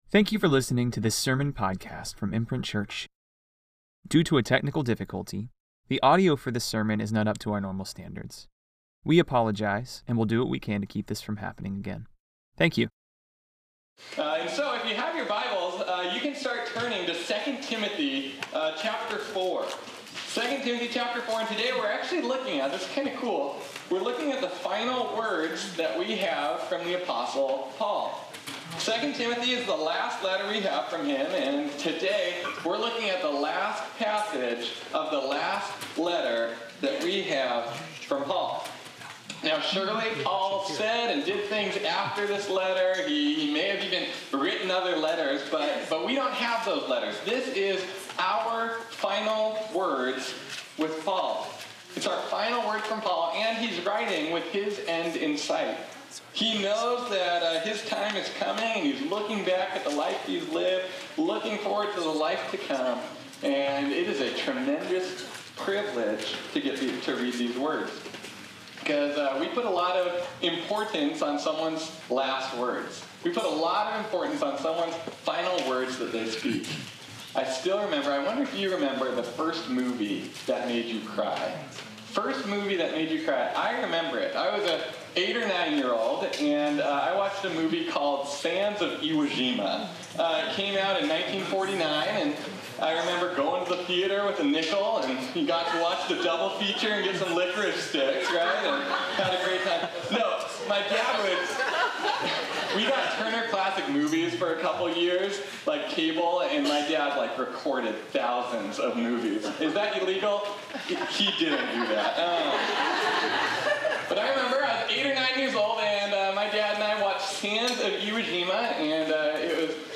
This sermon was originally preached on Sunday, September 1, 2019.